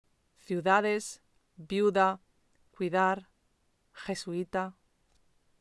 • Homogéneos: viuda, cuida, huir: [bíu̯ða] o [bjúða], [kwíða], [wíɾ]
Diptongos homogéneos